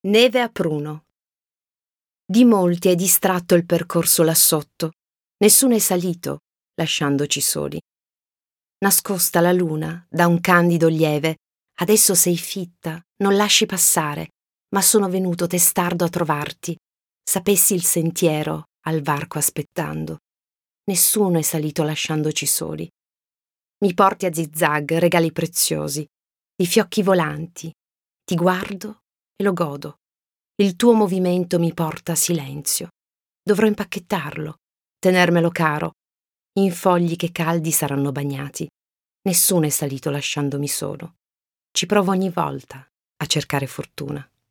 " ...SULLE NOSTRE OSSA" - EBOOK/AUDIOLIBRO